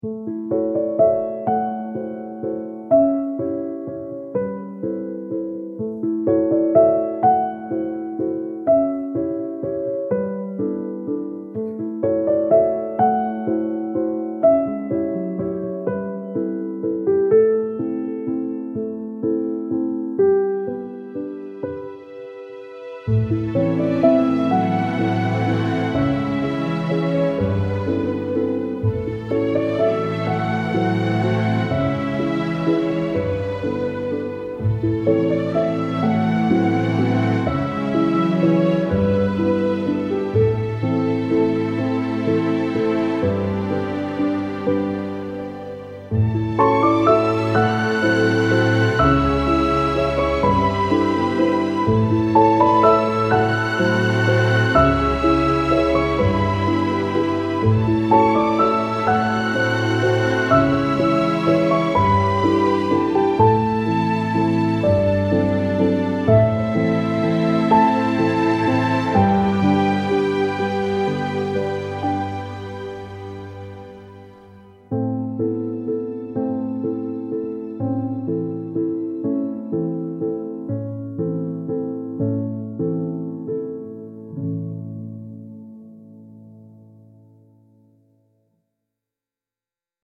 vintage warm orchestral piece evoking old family home movies